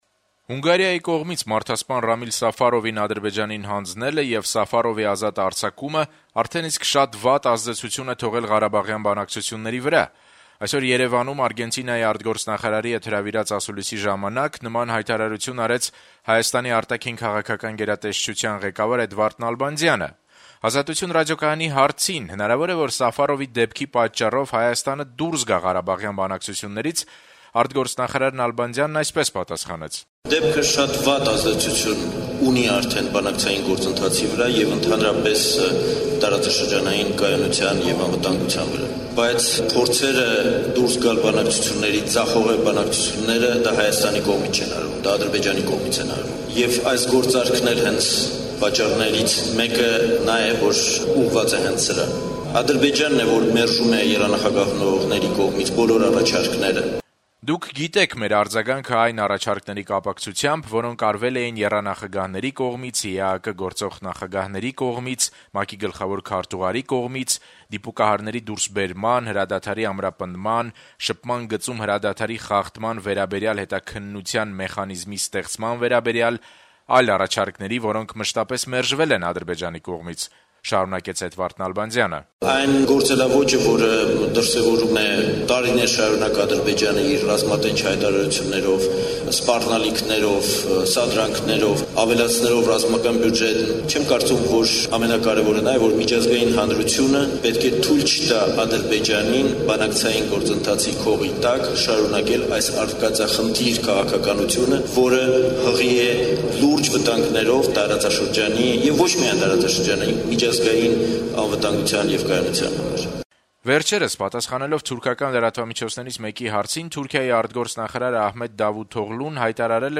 Հայաստան -- Հայաստանի եւ Արգենտինայի արտգործնախարարների համատեղ ասուլիսը Երեւանում, 4-ը սեպտեմբերի, 2012թ․